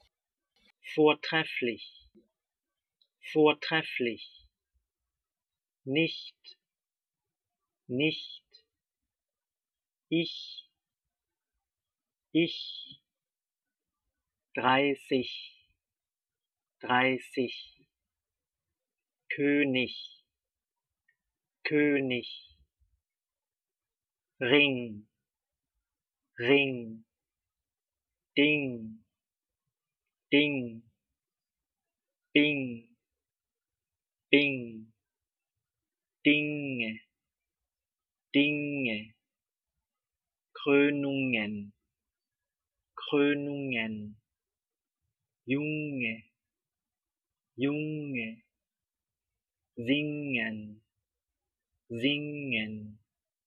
Teil 2: ch / ng